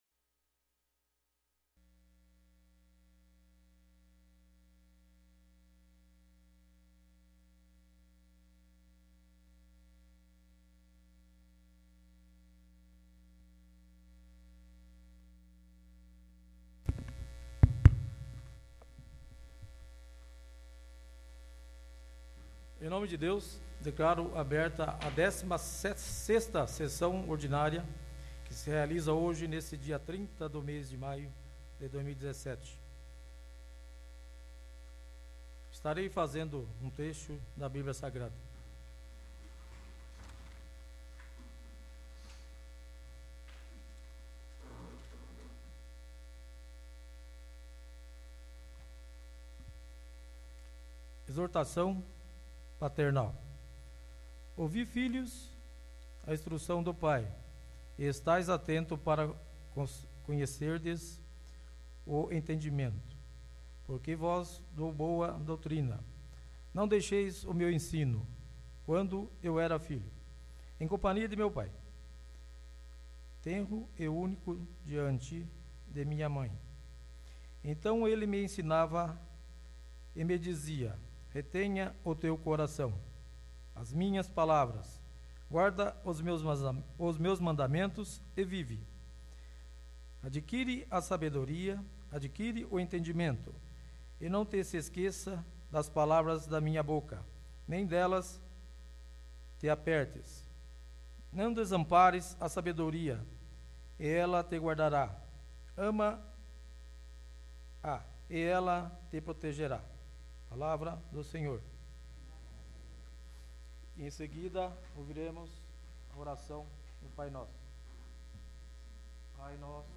16º. Sessão Ordinária